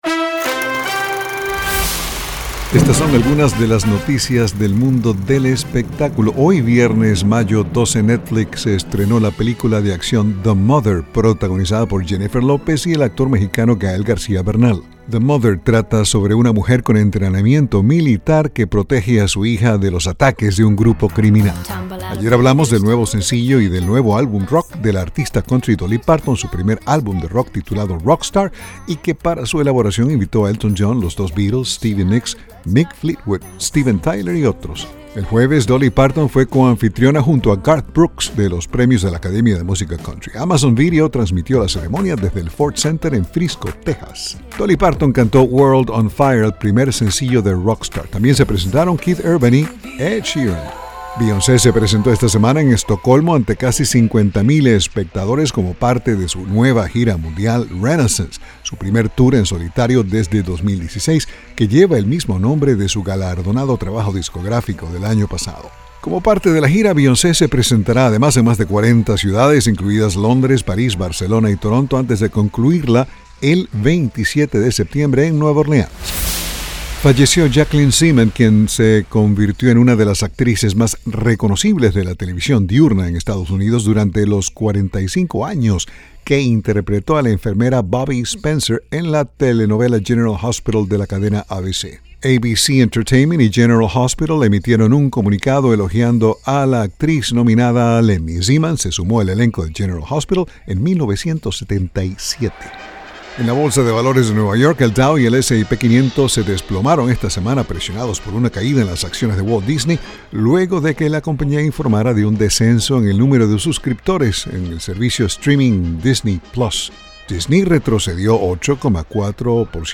Son las noticias del mundo del espectáculo